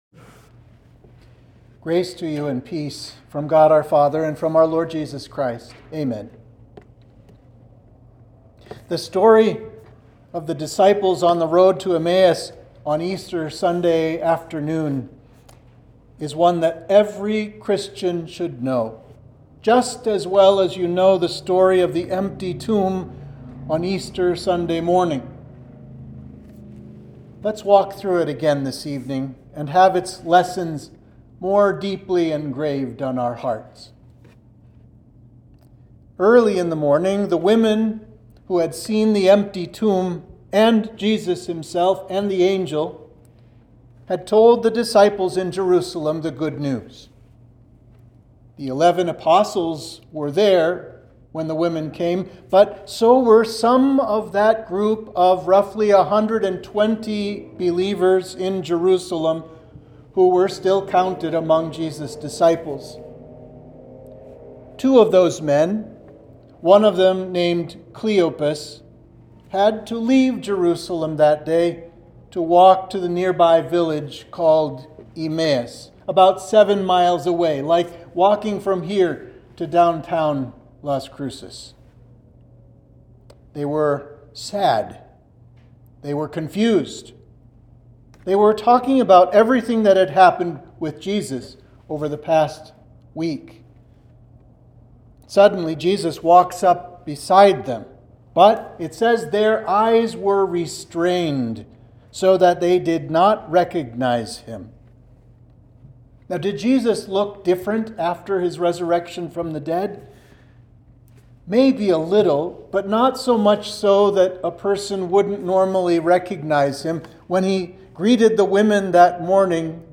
Sermon for the week of Easter